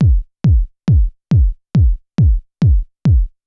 BD        -L.wav